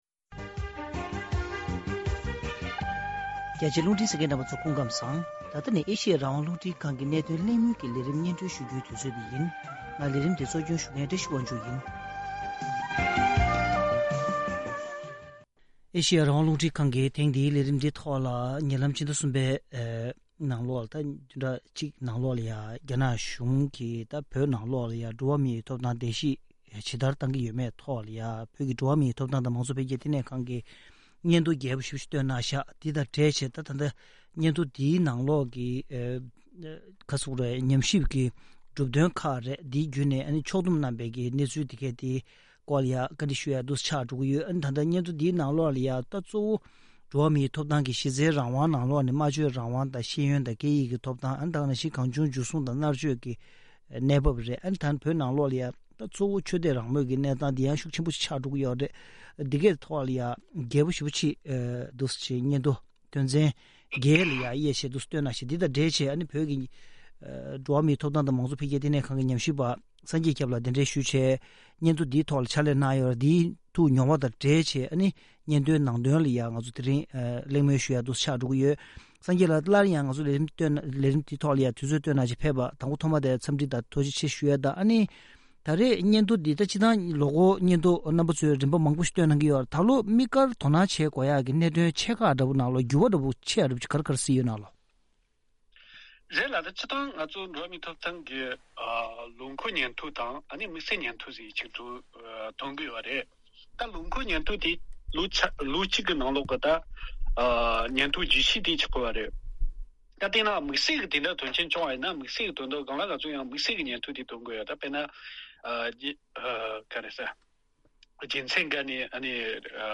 གླེང་མོལ་ཞུས་པའི་ལས་རིམ།